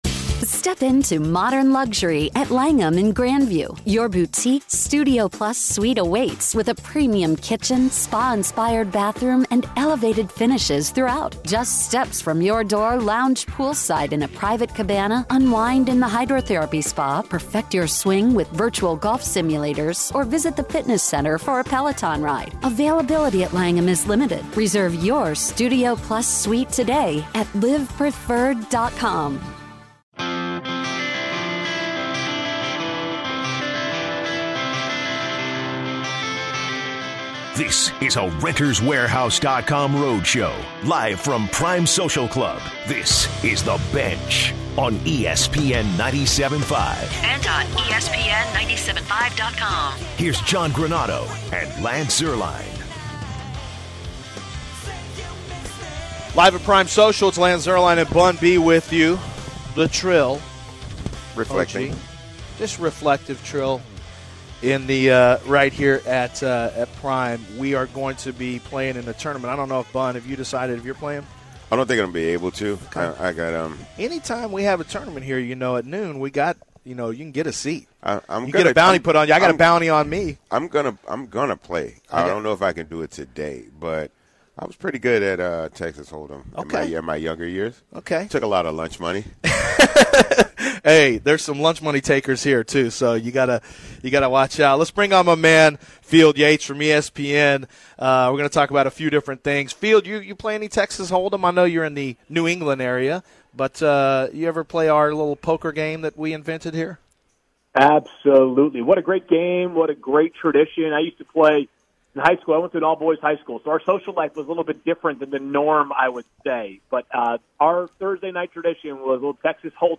After the interview, the guys talk about Lebron and if he were to join the Rockets. They continue the conversation by mentioning to pay attention to who is on the team, no matter the sport.